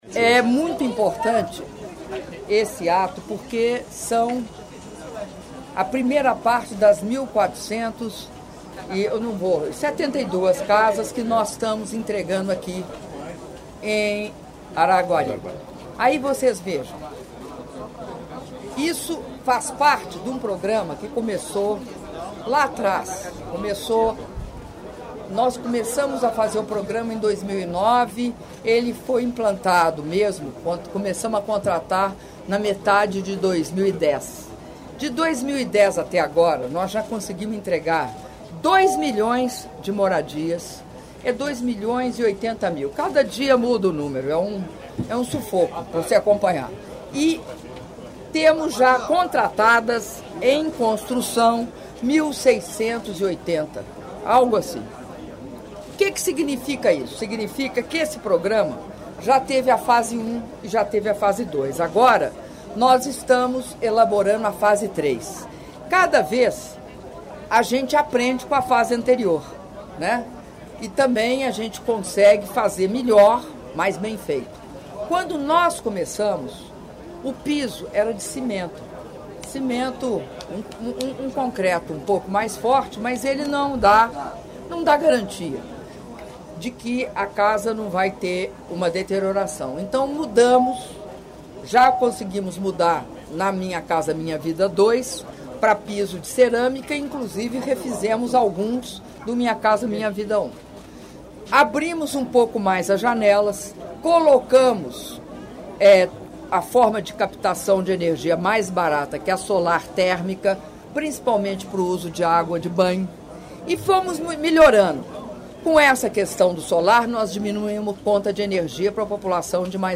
Áudio da entrevista coletiva concedida pela Presidenta da República, Dilma Rousseff, após cerimônia de entrega de 1.472 unidades habitacionais dos Residenciais Bela Suíça II e III, do Programa Minha Casa Minha Vida - Araguari/MG (07min49s) — Biblioteca